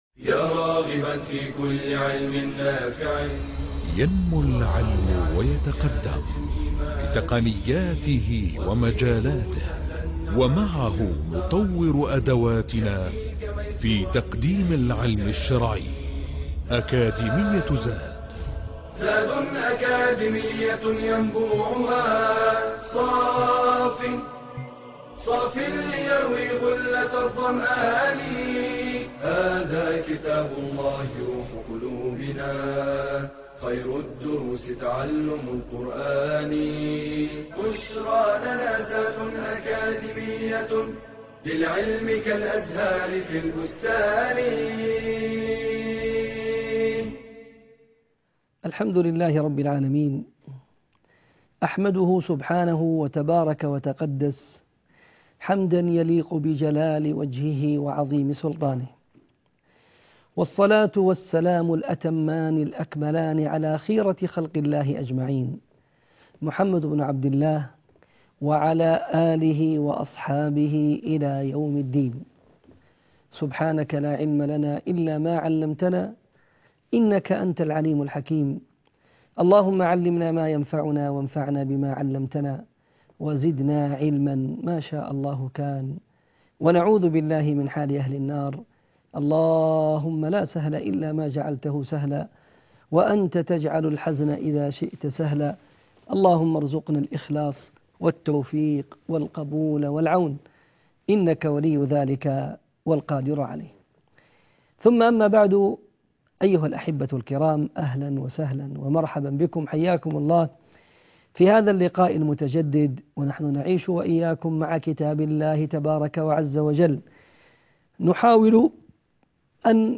المحاضرة العاشرة- سورة النازعات من الاية